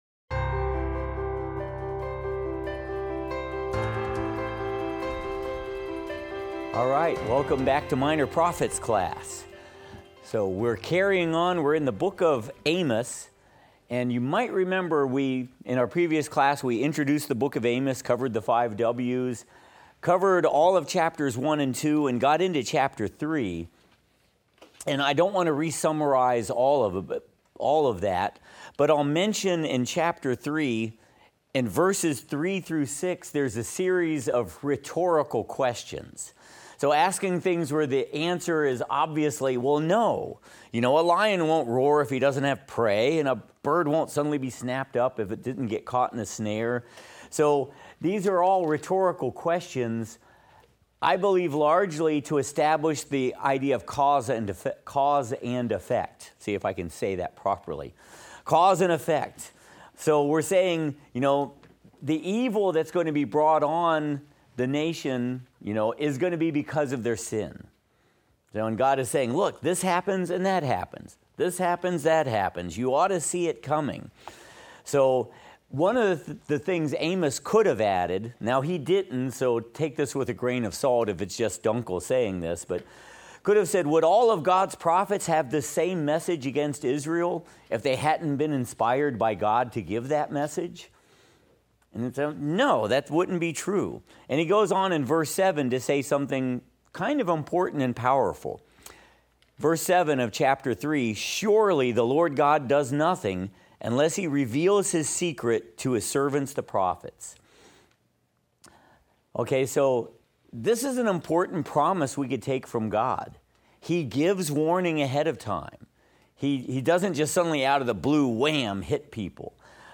Minor Prophets - Lecture 10 - audio.mp3